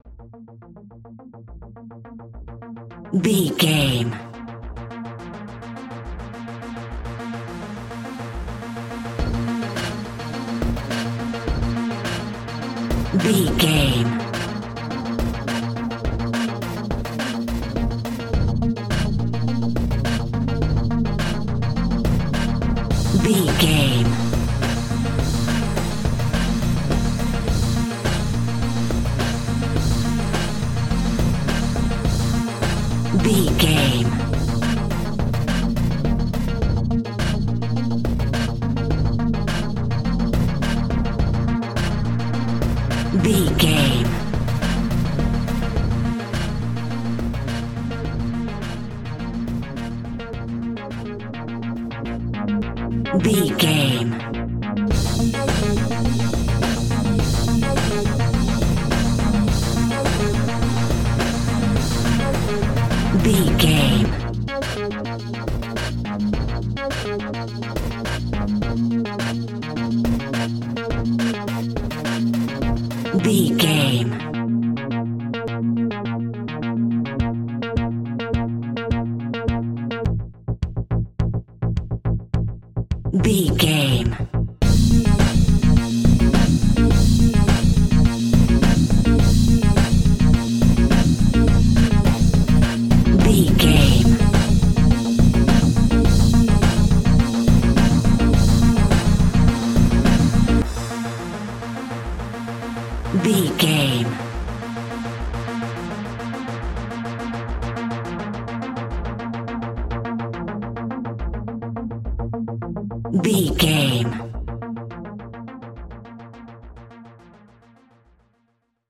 Epic / Action
Fast paced
Aeolian/Minor
B♭
driving
intense
energetic
bass guitar
drums
drum machine
synthesiser
house
techno
electro house
club music
synth leads
synth bass